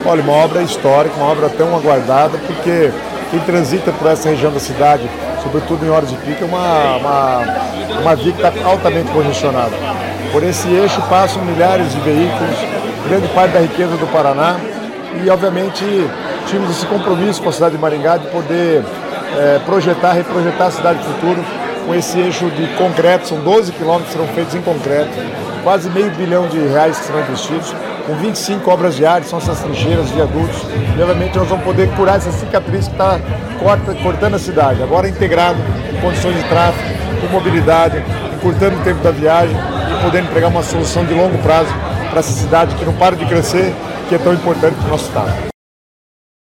Sonora do secretário das Cidades, Guto Silva, sobre a formalização do convênio para a nova etapa da duplicação do Contorno Sul de Maringá